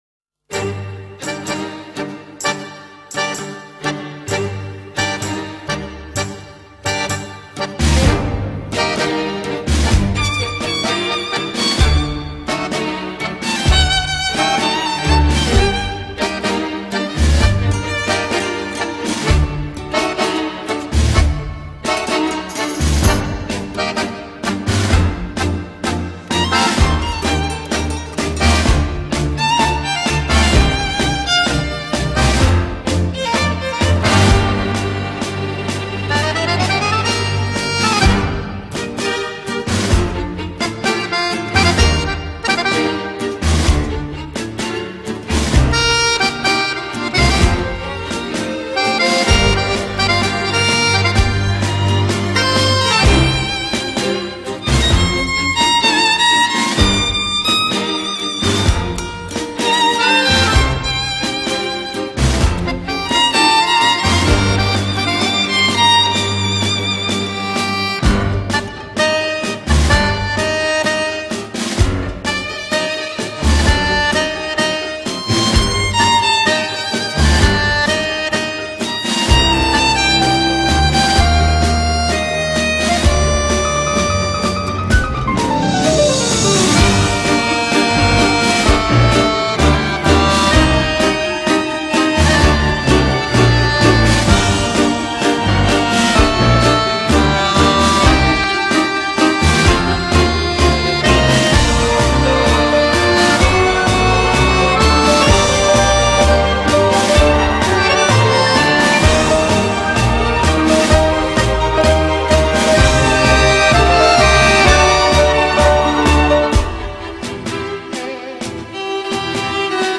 Tango-Instrumental.mp3